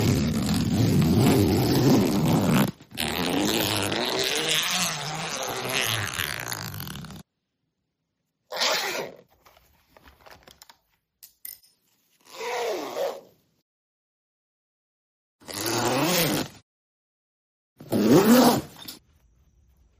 Office Ambience
Office Ambience is a free ambient sound effect available for download in MP3 format.
# office # work # keyboard # ambient About this sound Office Ambience is a free ambient sound effect available for download in MP3 format.
445_office_ambience.mp3